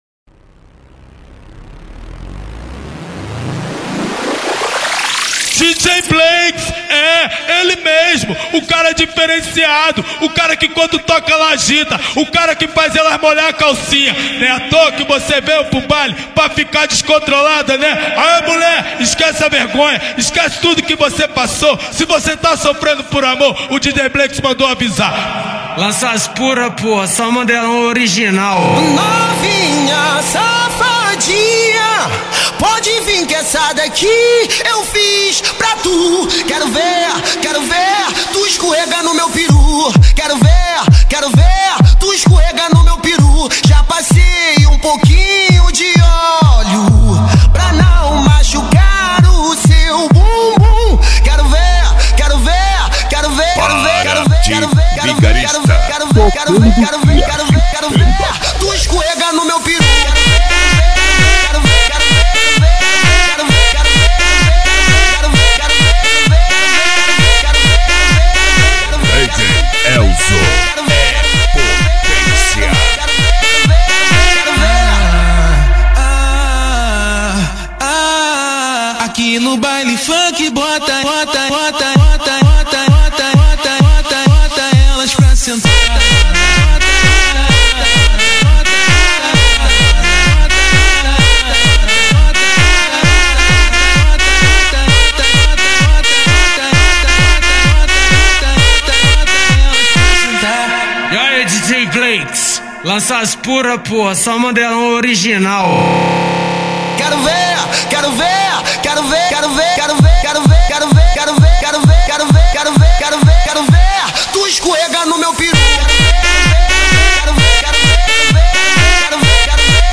Eletro Funk